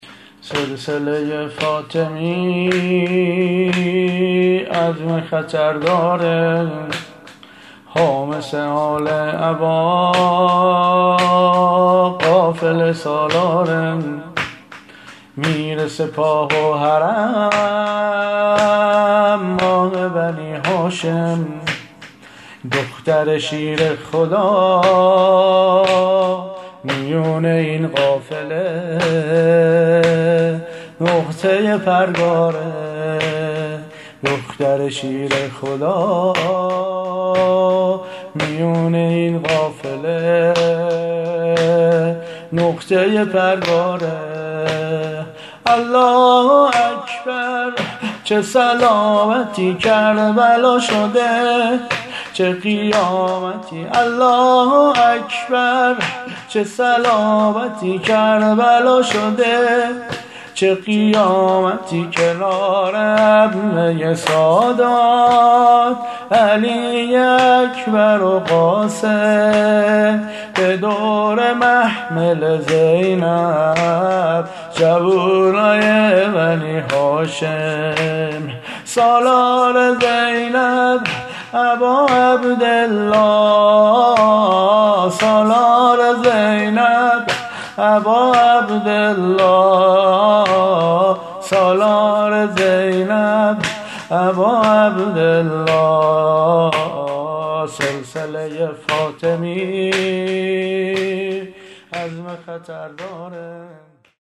زمینه ورود به کربلا -( سلسله ی فاطمی، عزم خطر داره )